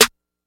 Snare (2).wav